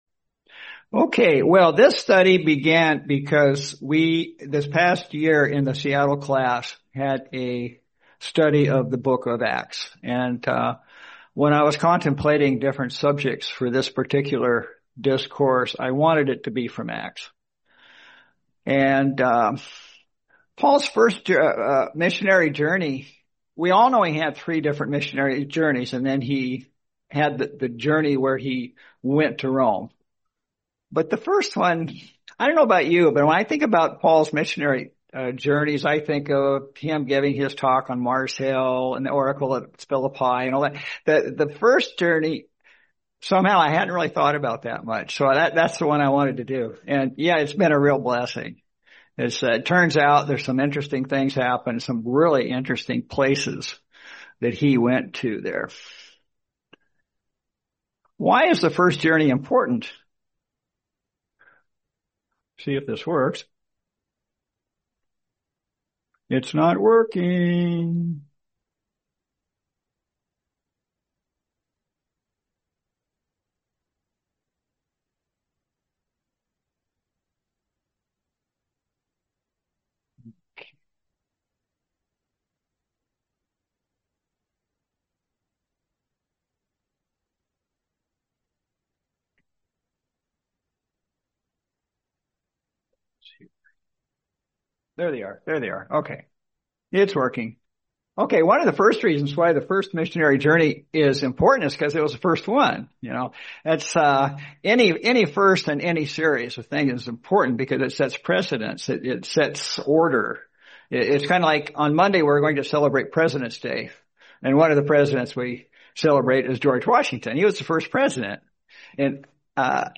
Series: 2026 Sacramento Convention
Service Type: Sermons